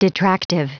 Prononciation du mot detractive en anglais (fichier audio)